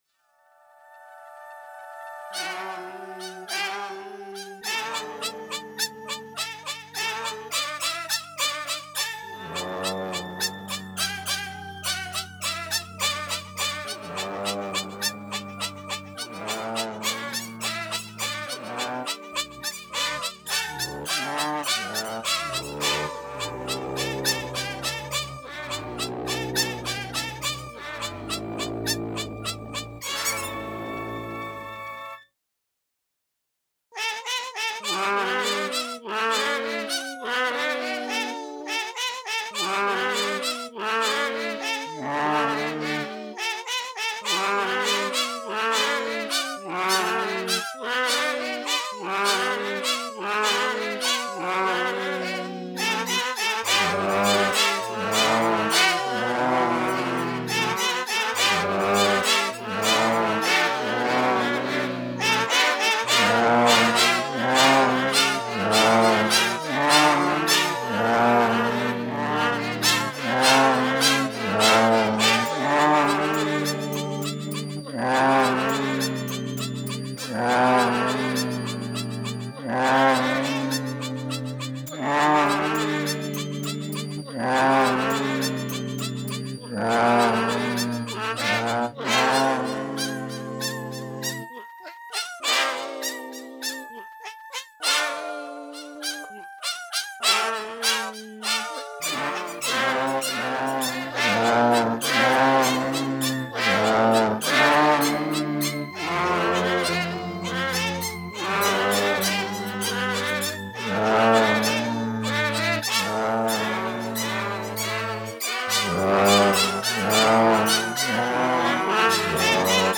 Meow